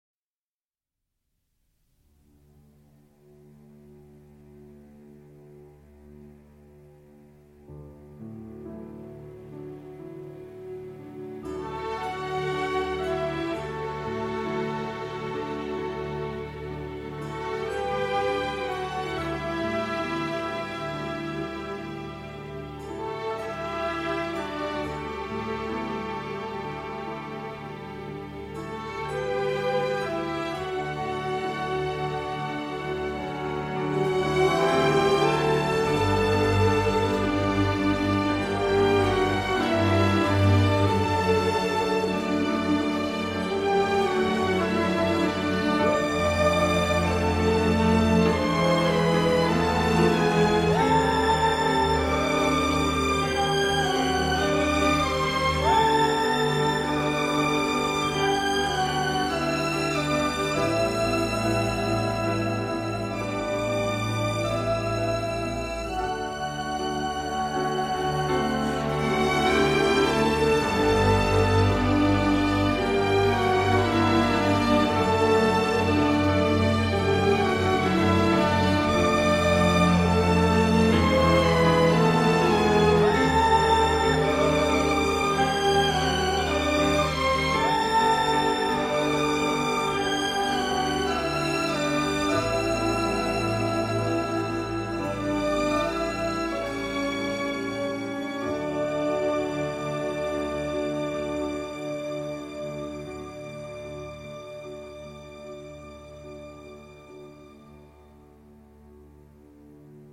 Un suspense heurté et malaisant
piano, cordes